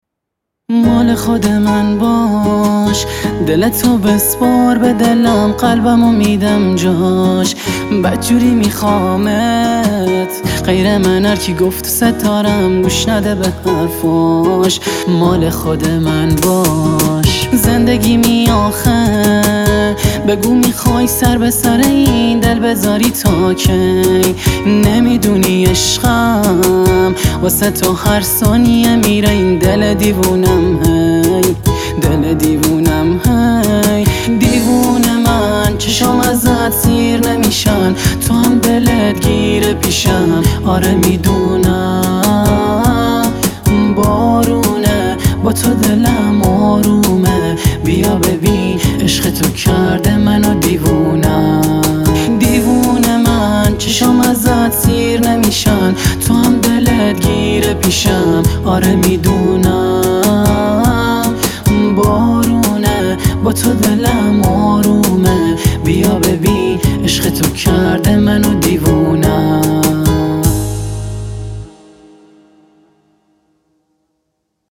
” با گیتار ”